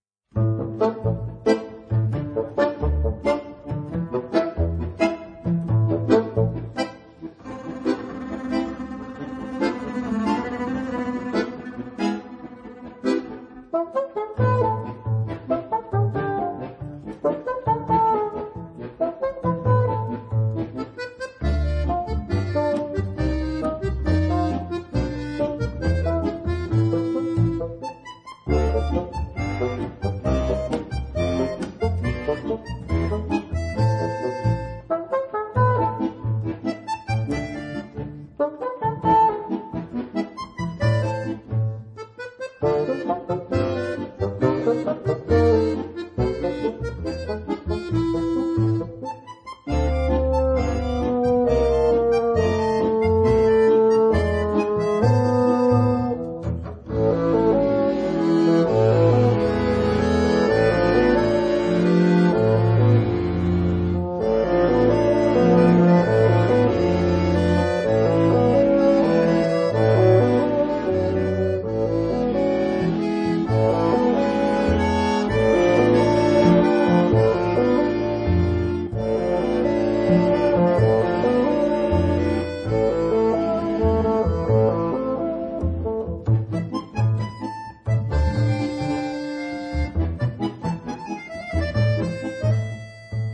特別是，音色上的控制，巴黎音樂院的木管傳統，的確是強。